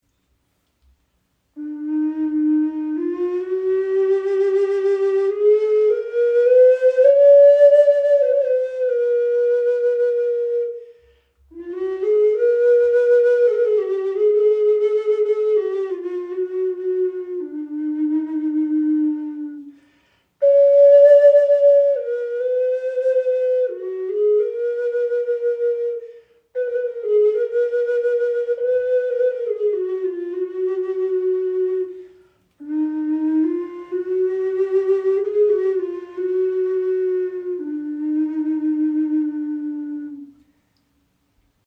Okarina aus einem Aststück | E4 in 432 Hz | Aeolian Stimmung | ca. 23 cm
Klein, handlich, klangvoll – eine Okarina mit Seele
Handgefertigte 6 Loch Okarina aus Akazienast – klarer, warmer Klang in Aeolischer Stimmung in E (432 Hz), jedes Stück ein Unikat.
Die Okarina spricht besonders klar an und erklingt in der Aeolischen Stimmung in E, fein abgestimmt auf 432 Hz – ein Ton, der Herz und Geist in harmonische Schwingung versetzt.
Trotz ihrer handlichen Grösse erzeugt sie einen angenehm tiefen, warmen Klang – fast ebenbürtig zur nordamerikanischen Gebetsflöte.